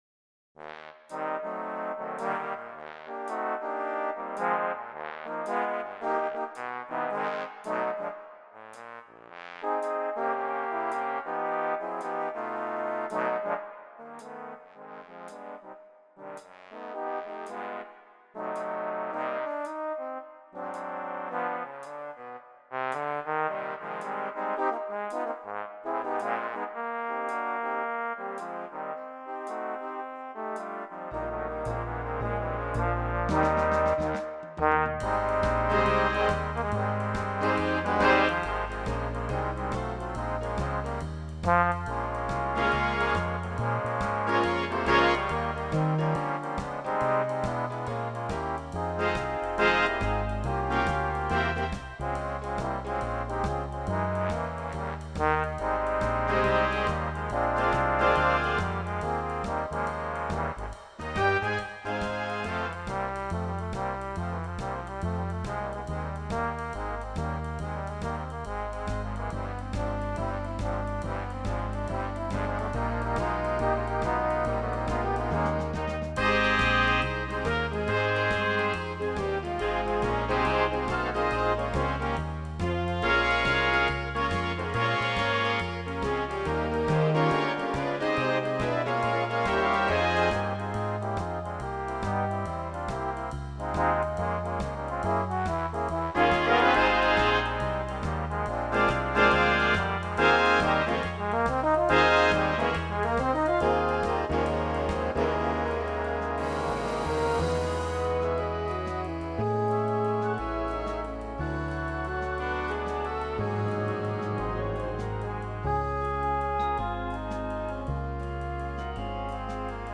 Gattung: Solo für 4 Posaunen und Blasorchester
Besetzung: Blasorchester